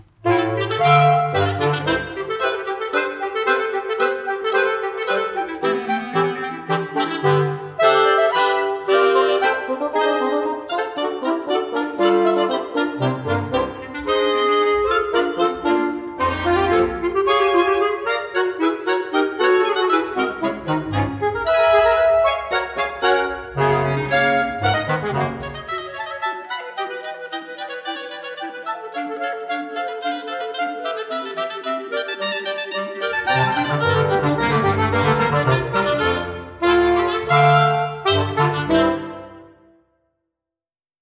Rose Canyon Harmonie at Coos History Museum Plaza Stage
A unique reed quintet
bassoon
oboe
clarinet
saxophone
bass clarinet.
Here are some clips from the Rose Canyon Harmonie performance: